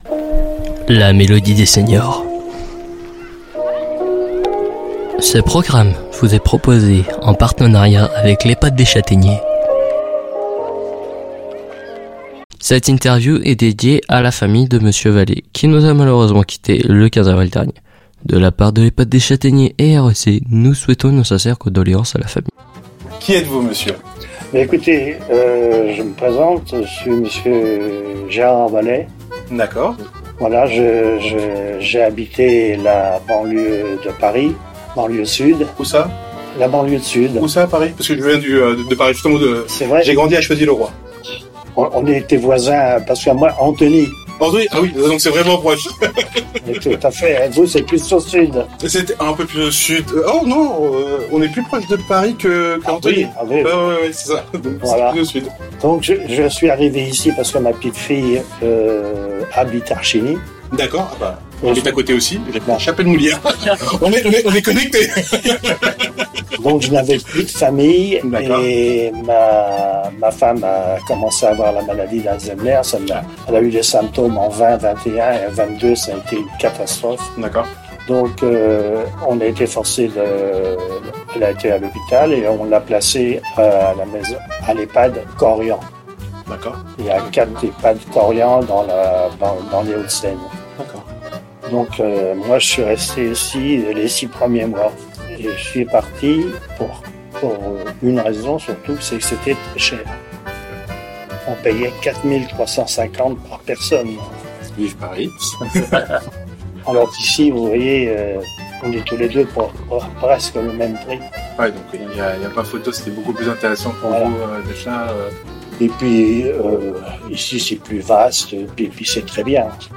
Une rencontre en musique des habitants de l’ehpad des chataigners.